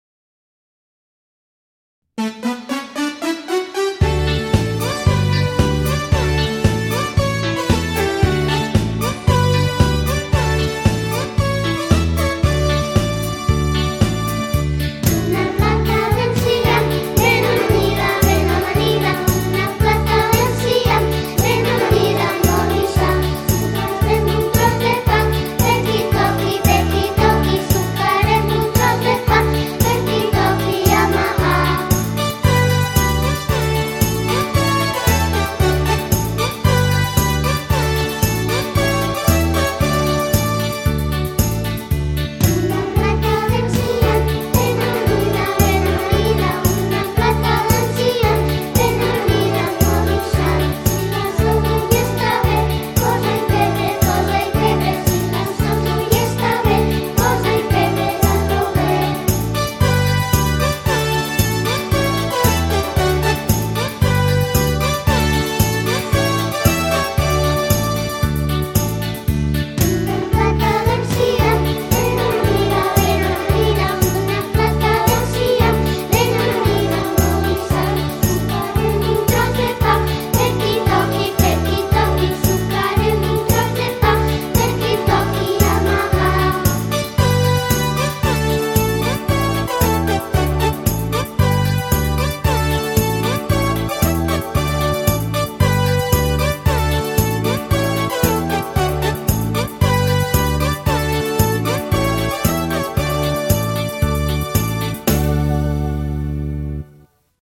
Les cançons que us presento són algunes de les que es van cantar a Cicle Inicial.